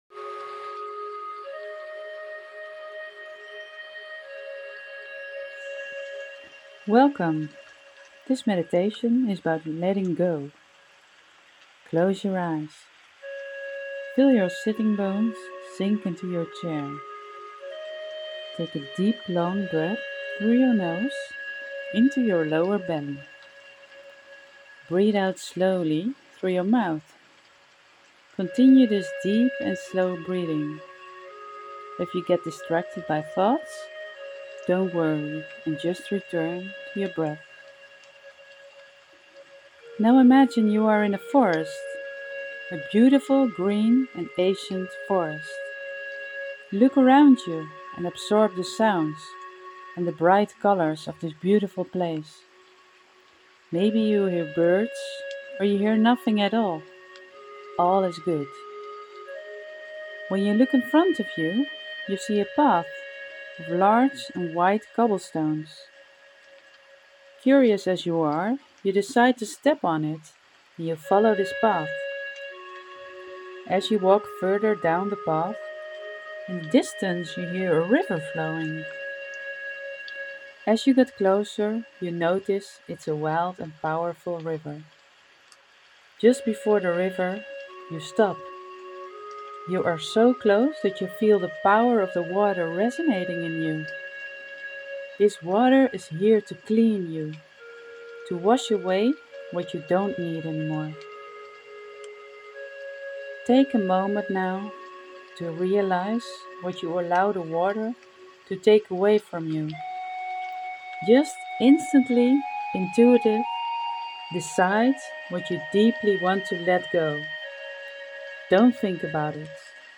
hier een meditatie voor een voorproefje!
Letting_go_water_meditation_(1).mp3